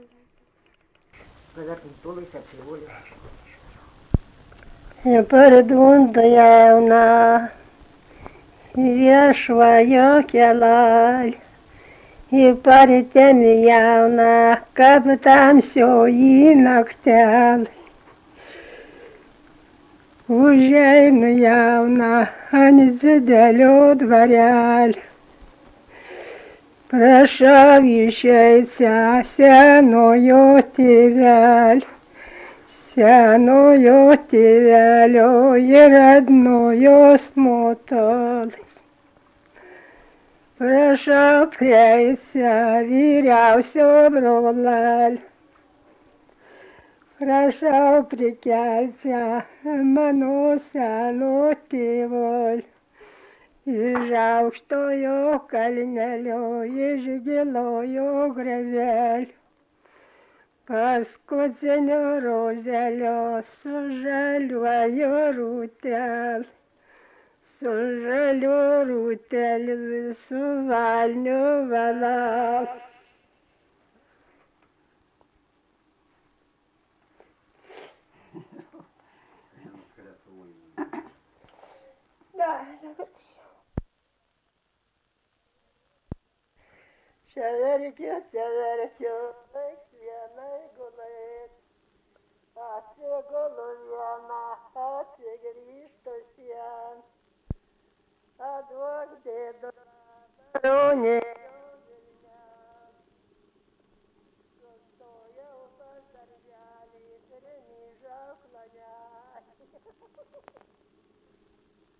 Dalykas, tema daina
Atlikimo pubūdis vokalinis
Dviese.